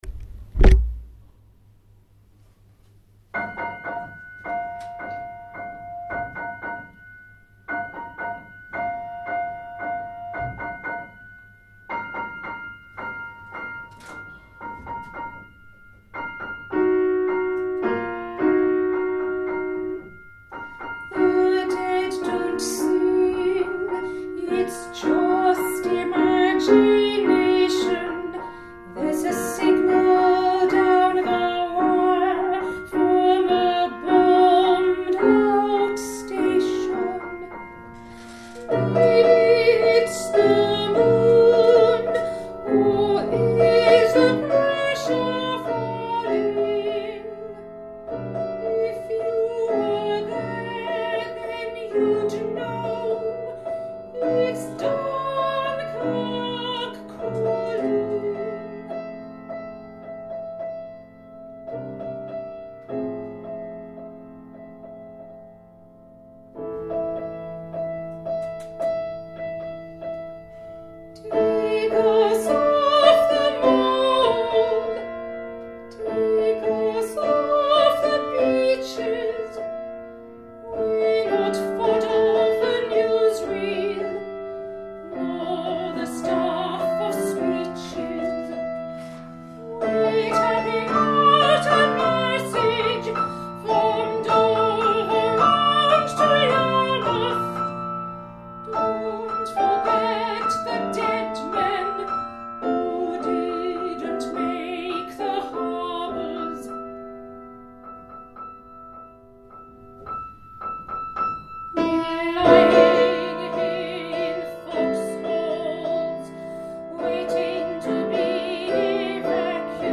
Vocal & Piano Audio of Dunkirk Calling